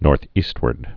(nôrth-ēstwərd, nôr-ēst-)